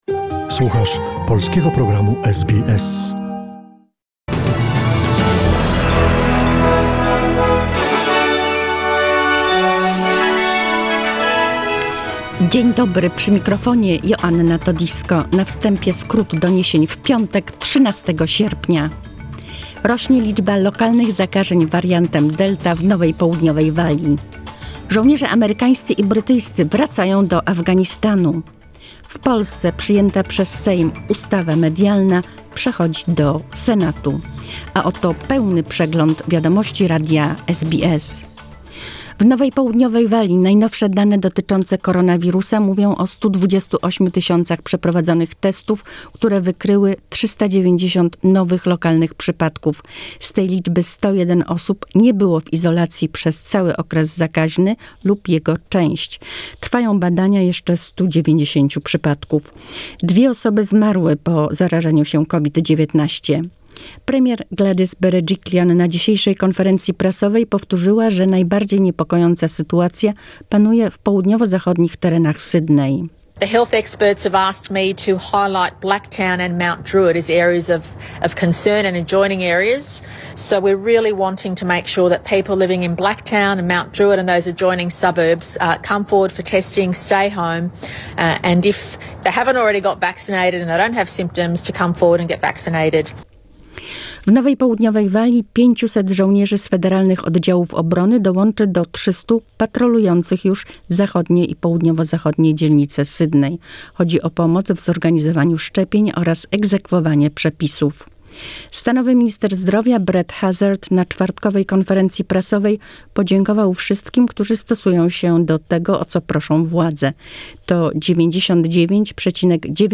SBS News in Polish, 13 August 2021